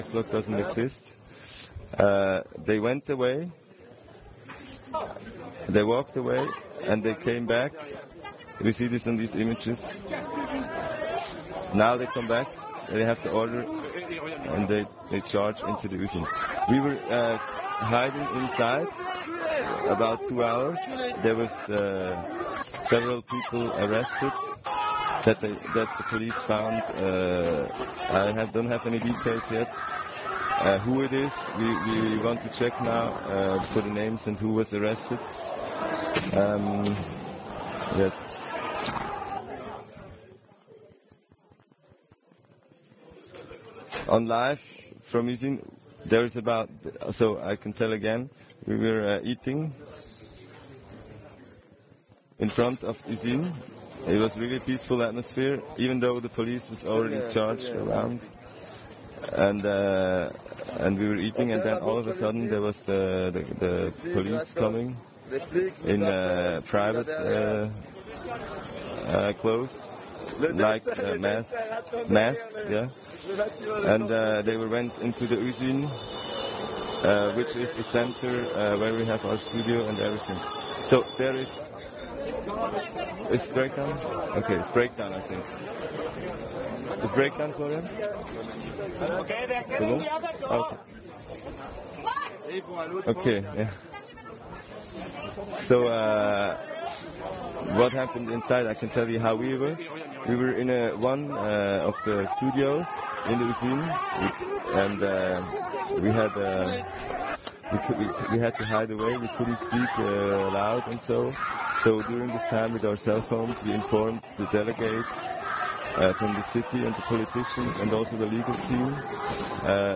interviews_english_italian.ogg